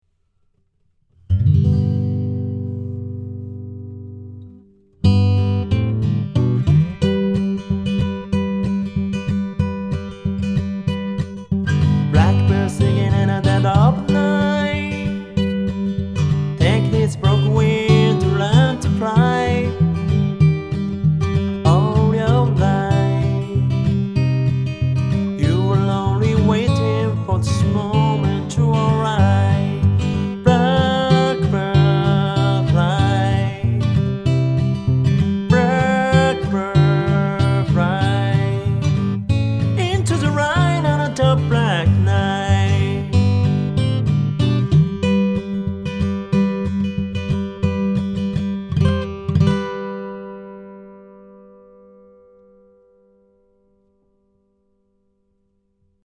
また、ほぼ特性の同じマイクが2本あることで、1本はギターのサウンドホールを狙い、 もう1本はボディー部分を狙えます。
ギター：M5 マッチド ペア
ボーカル：NT1-A